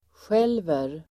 Uttal: [sj'el:ver]